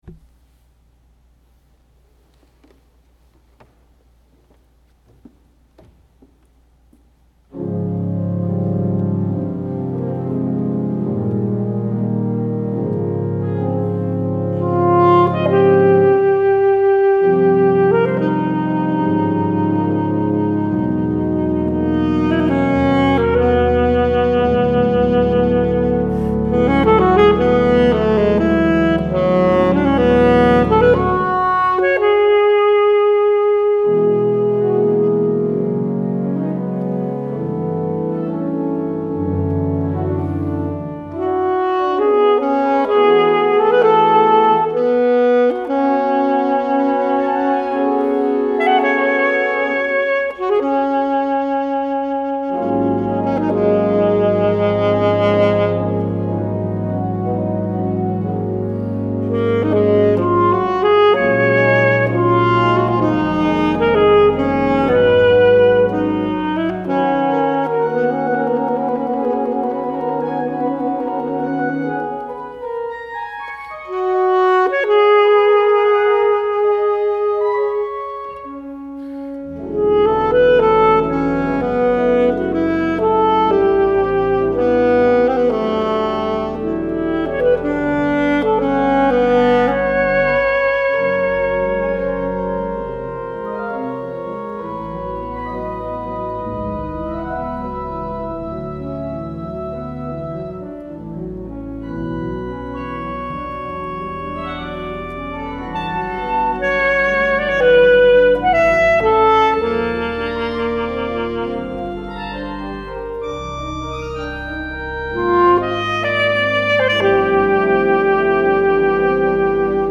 Voicing: Alto Saxophone